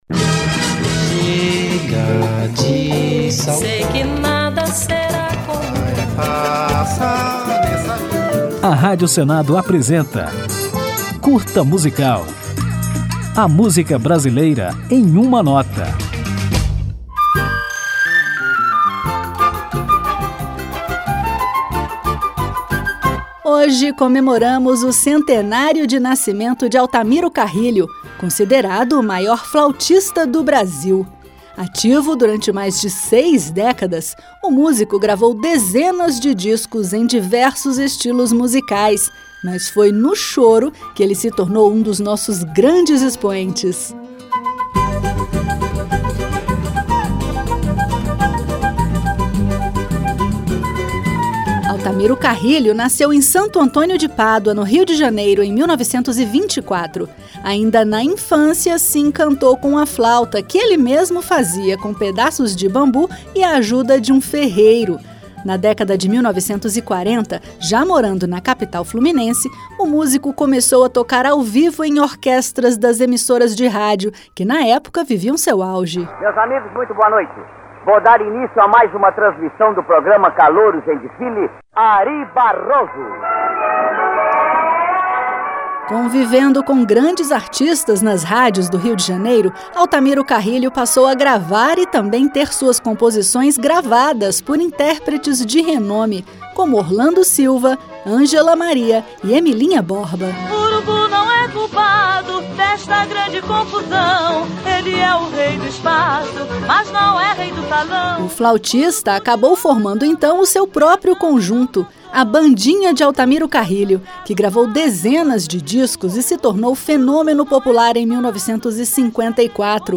Depois de conhecer um pouco da história do músico, você vai ouvir Altamiro Carrilho no choro Quem É Bom Já Nasce Feito.